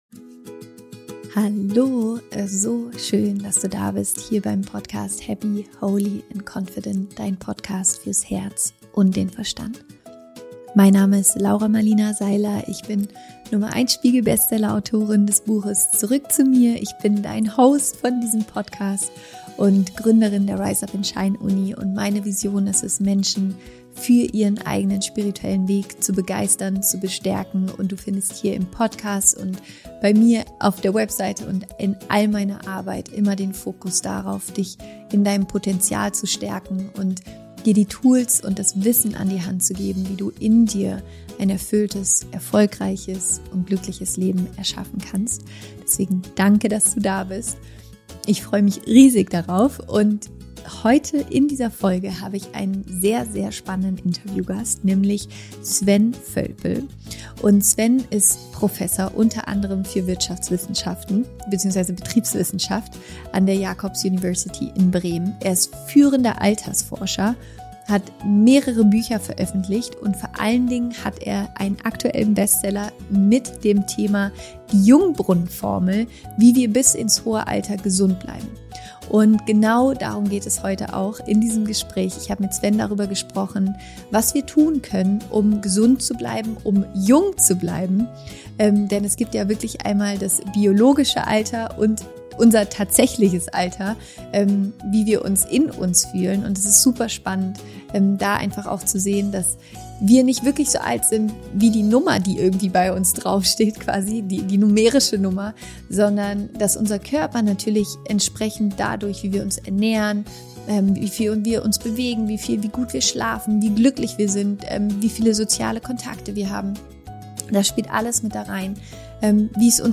Und deswegen gibt’s heute genau dazu eine neue Podcastfolge und ein Interview mit einem super spannenden Gast, quasi dem Meister der Lebensenergie und des Jungseins: